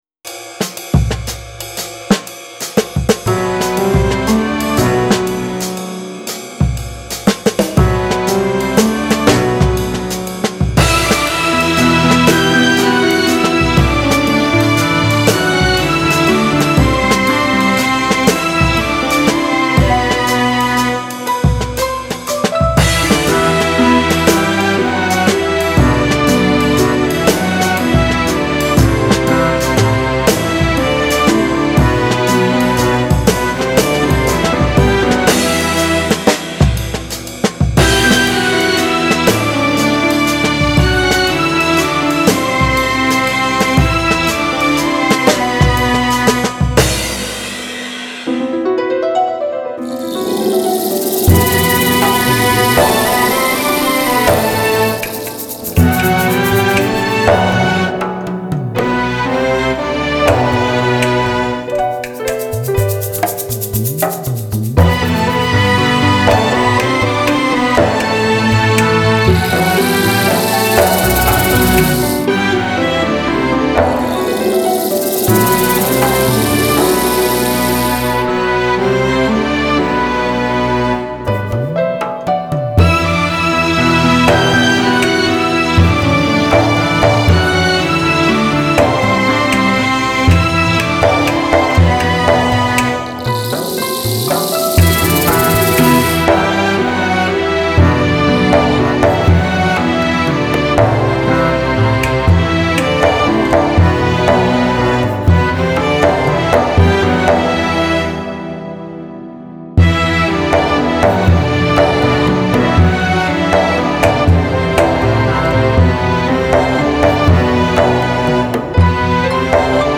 سبک عصر جدید , موسیقی بی کلام